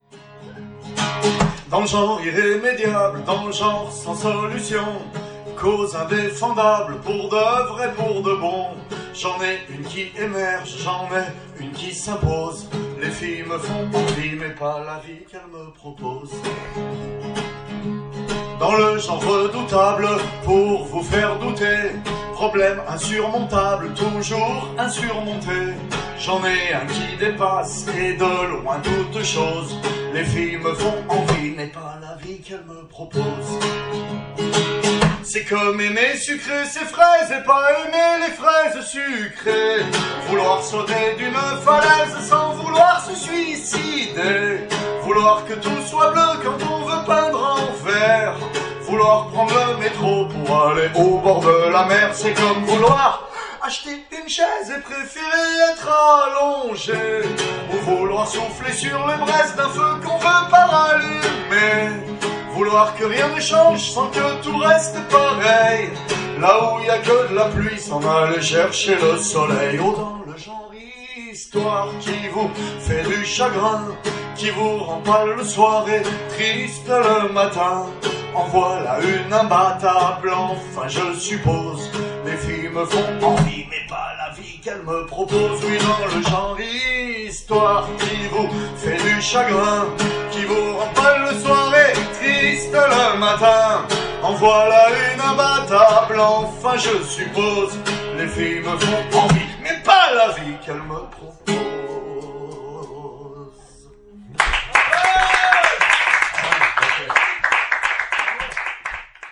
Soum-soum, Les chanteurs à guitare 4, 4 novembre 2016